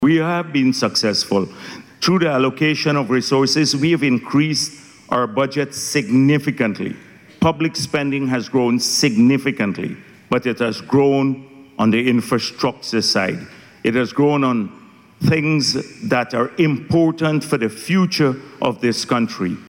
Day Two of the Energy Conference saw Vice President Dr. Bharrat Jagdeo reaffirming Guyana’s commitment to ensuring the safety of investments in its burgeoning oil and gas industry.